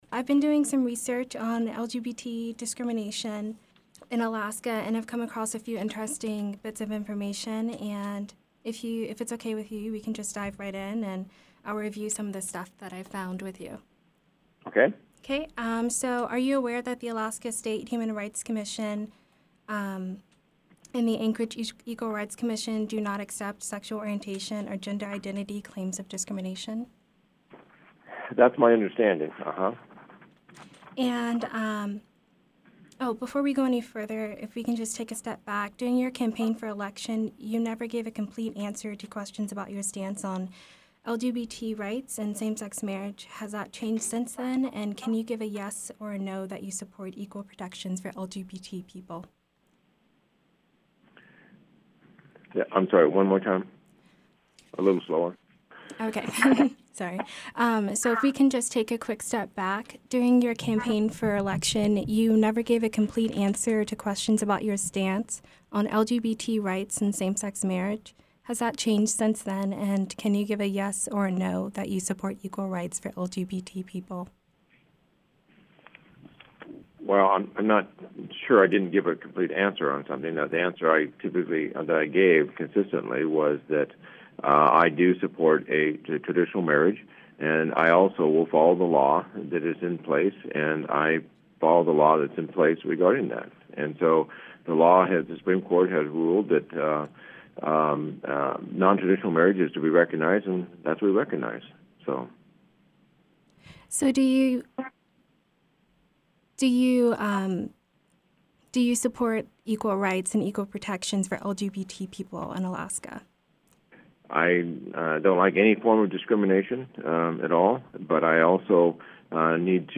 Q&A: Gov. Walker discusses LGBT rights